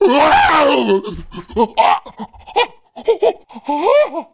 1 channel
BLUE-ELECTROCUTE2.mp3